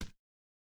Bare Step Stone Hard B.wav